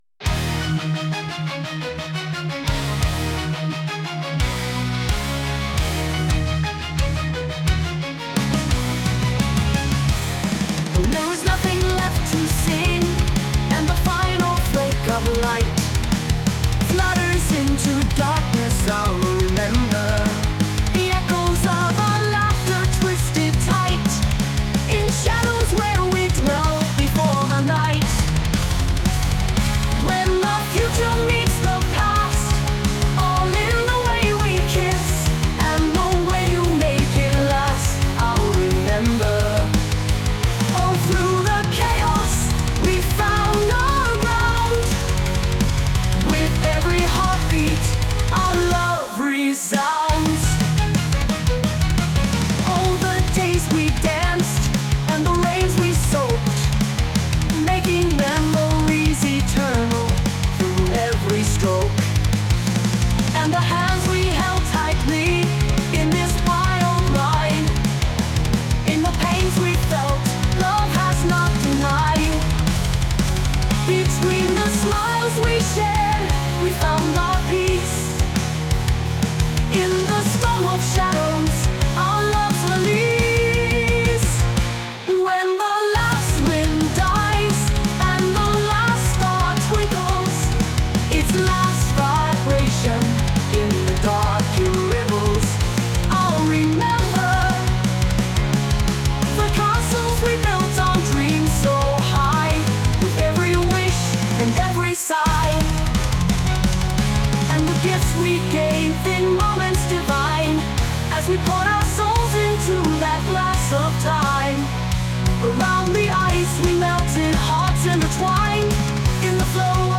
Ill-Remember-Metal-Version.mp3